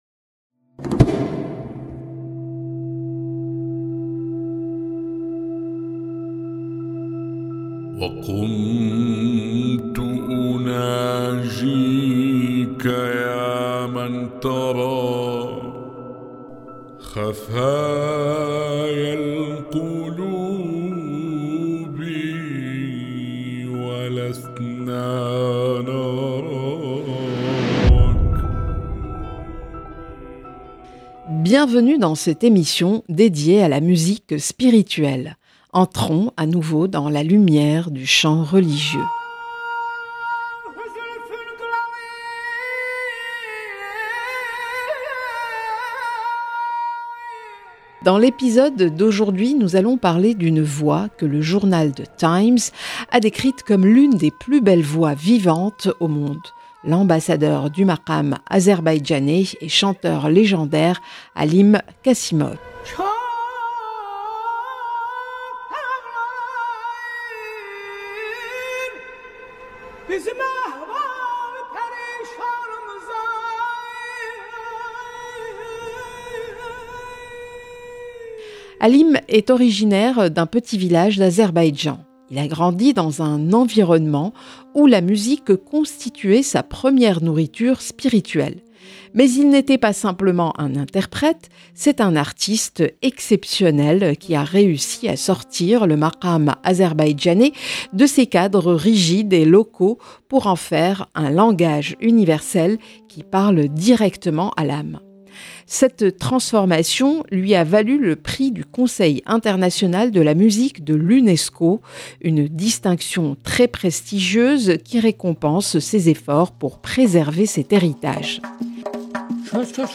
Alim Qasimov a réussi à faire passer le maqâm azerbaïdjanais d’un art local à un langage universel qui touche directement le cœur. Sa voix n’est pas seulement un chant de tarab, mais un voyage spirituel qui unit les fondements du maqâm à l’extase de l’amour divin.
une émission sur la musique spirituelle